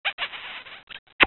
shot.mp3